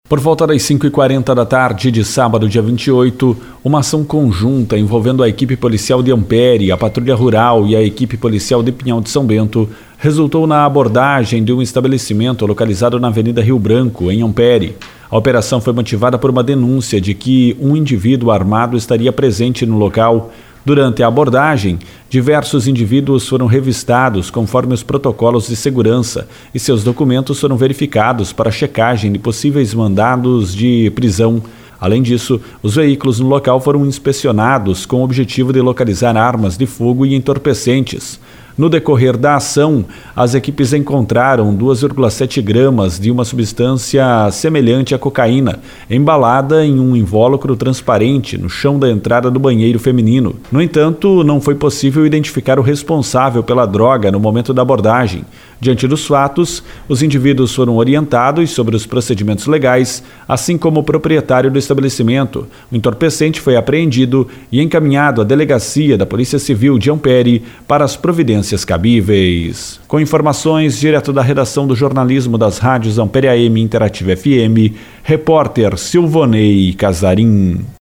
Áudio notícia. https